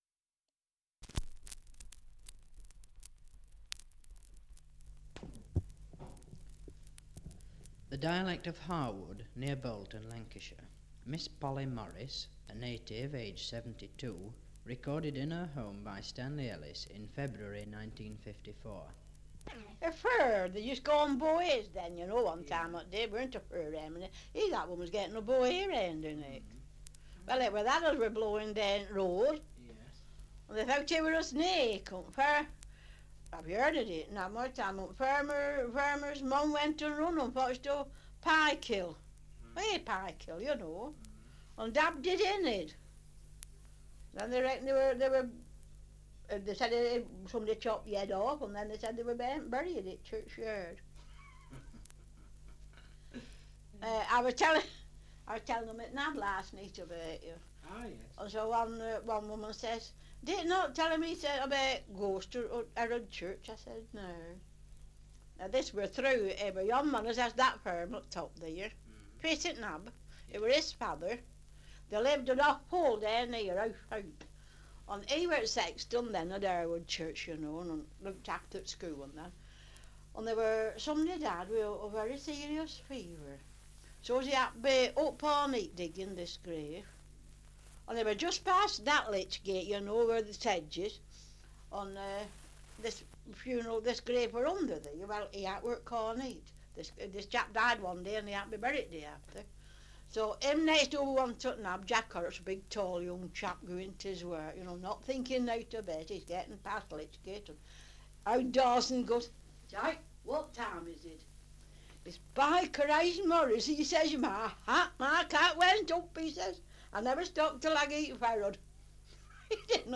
Survey of English Dialects recording in Harwood, Lancashire
78 r.p.m., cellulose nitrate on aluminium